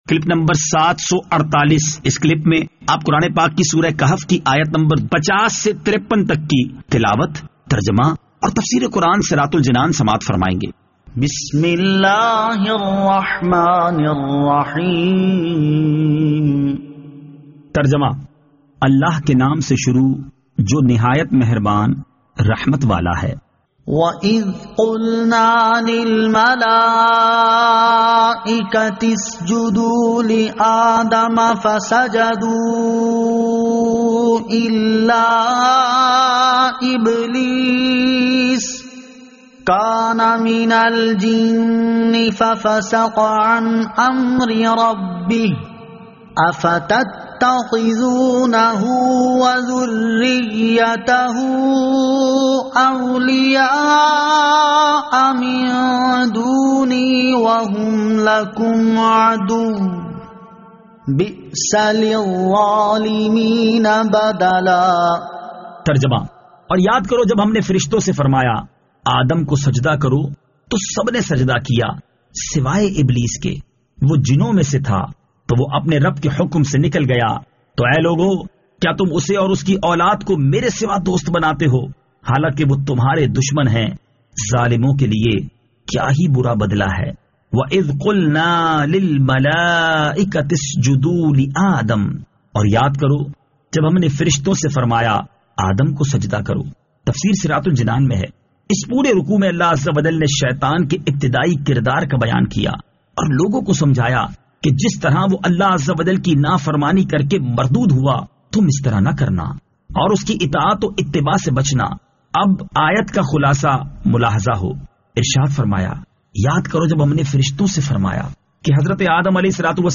Surah Al-Kahf Ayat 50 To 53 Tilawat , Tarjama , Tafseer